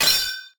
katana-clash3
Category: Sound FX   Right: Personal
Tags: sword